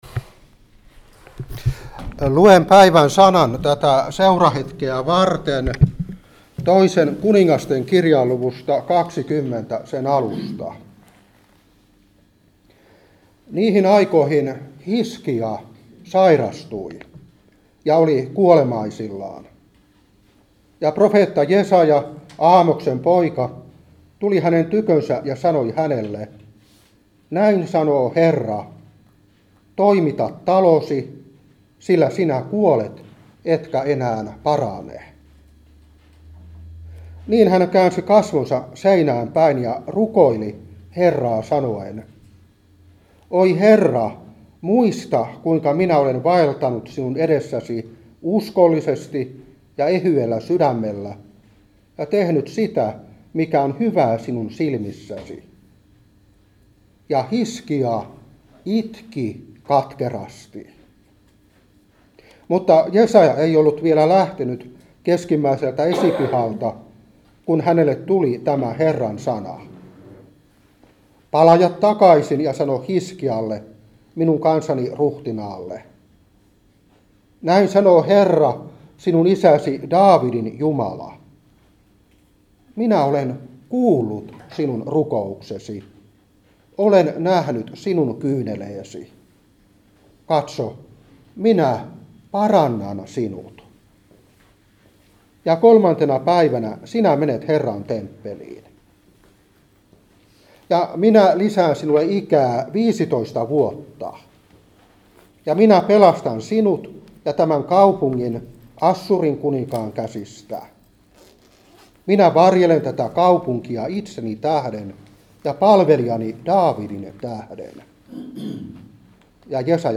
Seurapuhe 2023-3. 2.Kun.20:1-7.